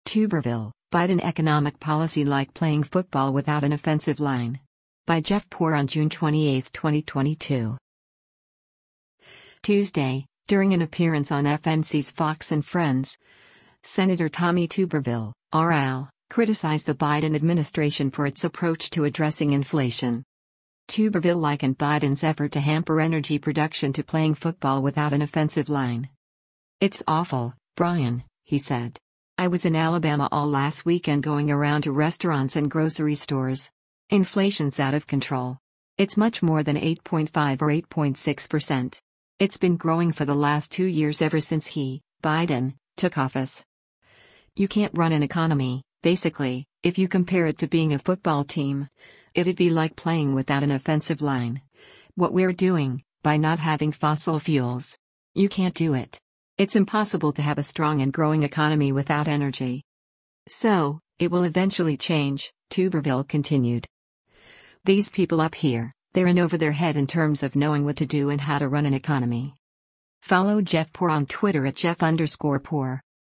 Tuesday, during an appearance on FNC’s “Fox & Friends,” Sen. Tommy Tuberville (R-AL) criticized the Biden administration for its approach to addressing inflation.